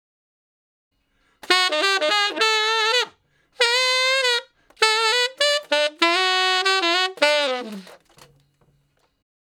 066 Ten Sax Straight (D) 17.wav